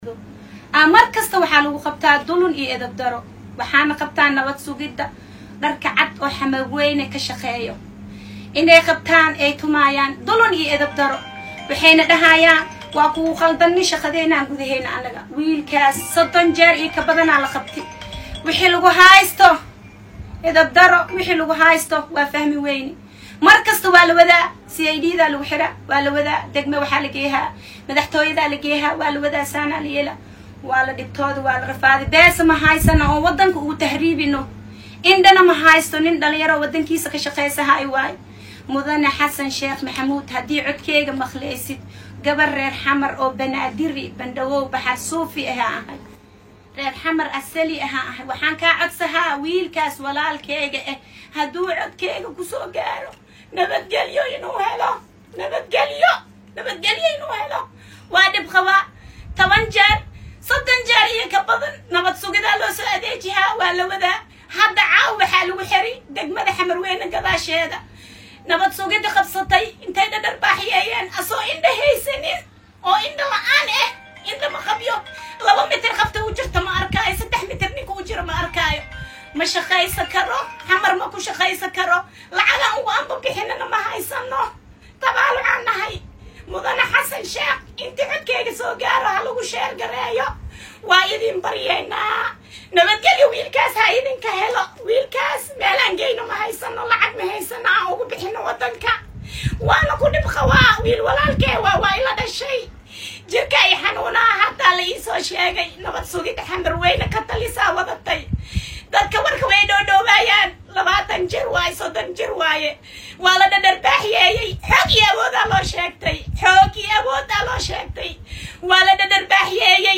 Mid ah Haweenka ku nool Degmada xamarweyne oo walaalkeed markasta ay iska xertaan ciidamada NISA si ay lacag ugu qaataan ayaa ka hadashay.